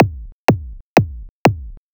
edm-kick-28.wav